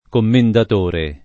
kommendat1re] s. m. — tronc. davanti a nome o cogn. (spec. se questo comincia con cons. semplice o muta + liquida): commendator Biondi, commendator Bruni; commendator (o commendatore) Antonio Valli, commendator (o commendatore) Stefano Monti